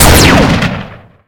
gun1.ogg